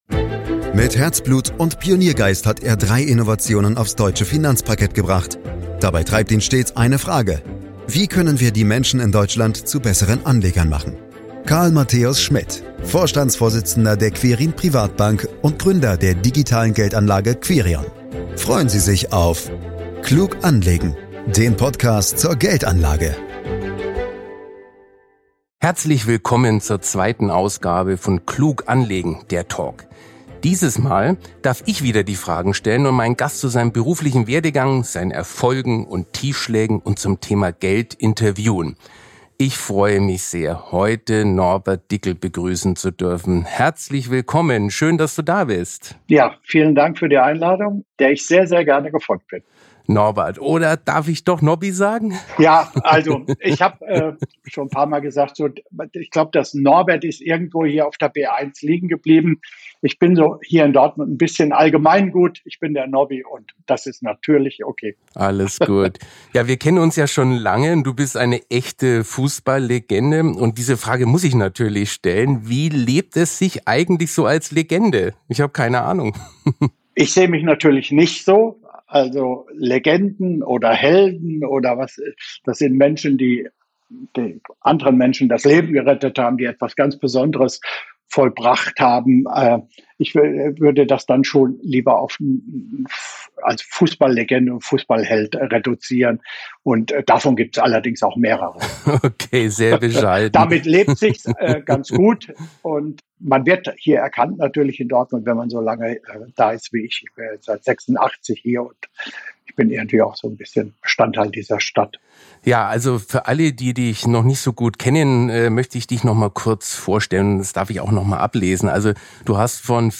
Unser zweiter Gast bei „klug anlegen DER Talk“ ist Norbert Dickel.